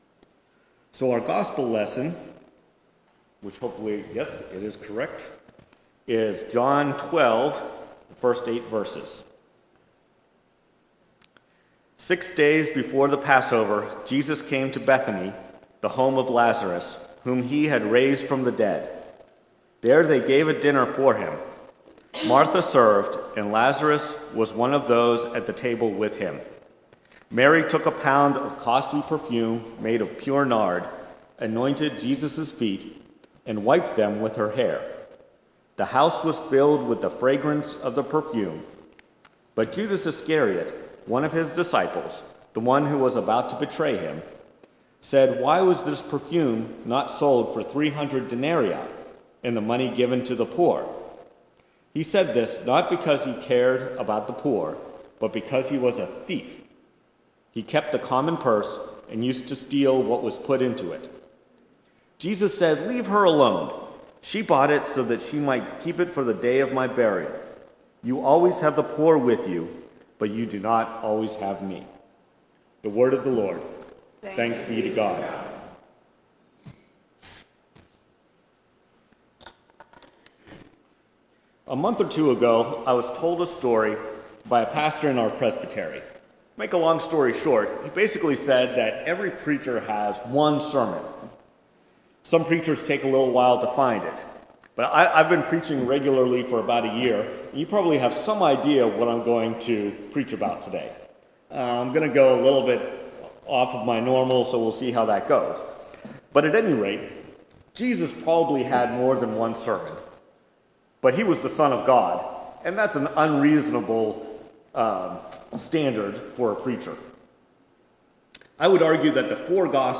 Preached at First Presbyterian Church of Rolla on April 3, 2022, the Fifth Sunday in Lent. Based on Philippians 3:4b-14; John 12:1-8.